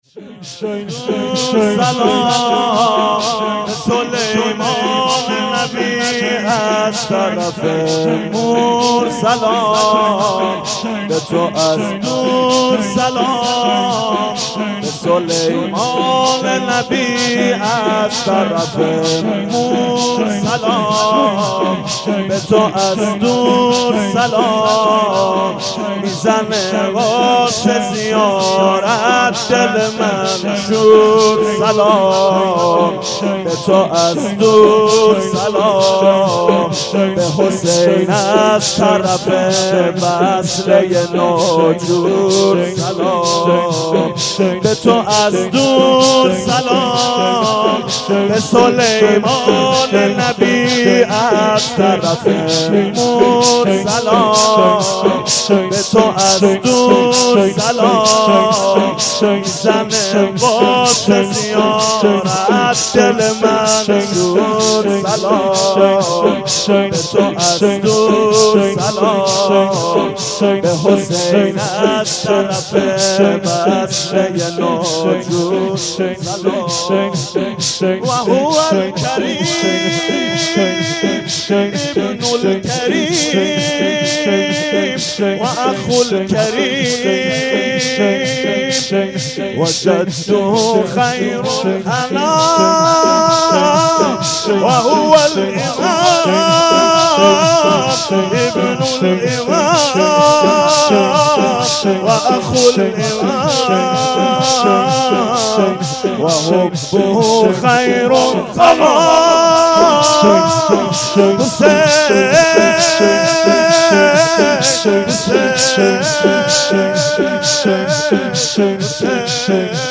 حسینیه بنی فاطمه(س)بیت الشهدا
0 0 شور شب چهارم محرم 97